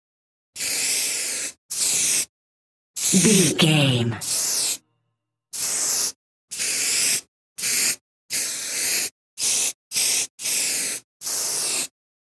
Graffiti aerosol spray medium
Sound Effects
urban
foley